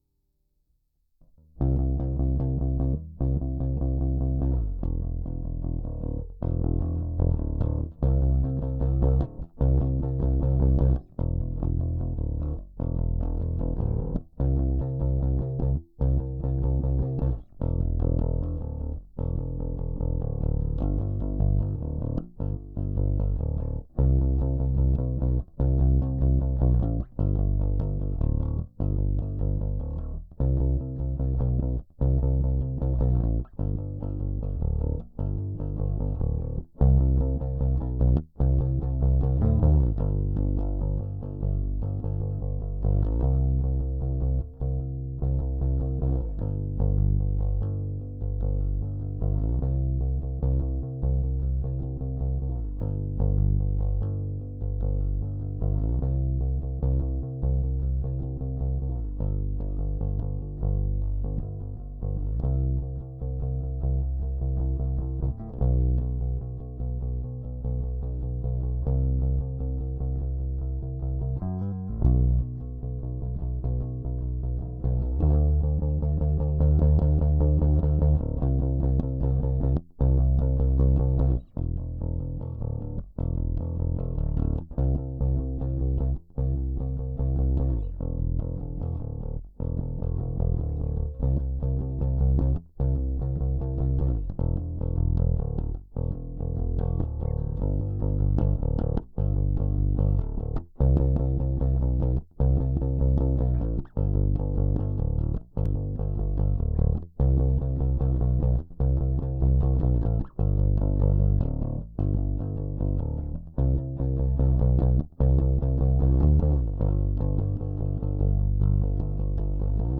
Philistine 150bpm Bass Mono
philistine-150bpm-bass-mono.wav